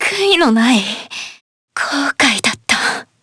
Miruru_L-Vox_Dead_jp.wav